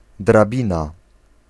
Ääntäminen
US UK : IPA : /ˈladə/ US : IPA : /ˈlæd.ɚ/